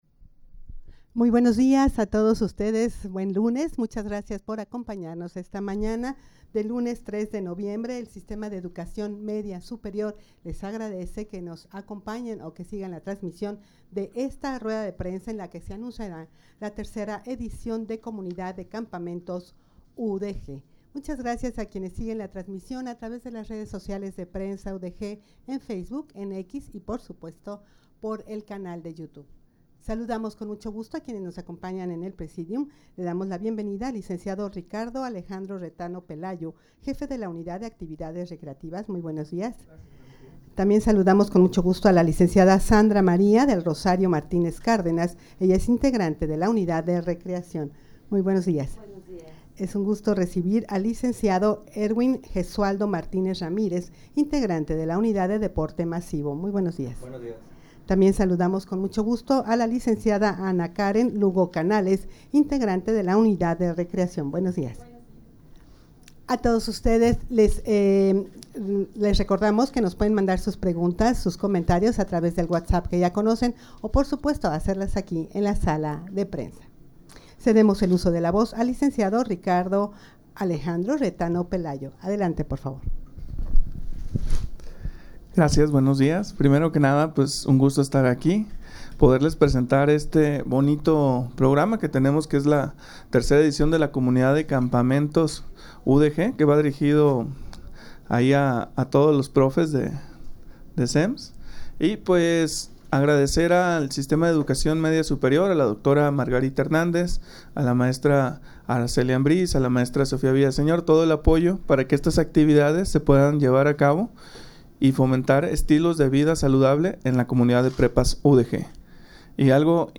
Audio de la Rueda de Prensa
rueda-de-prensa-para-anunciar-la-tercera-edicion-de-comunidad-de-campamentos-udeg.mp3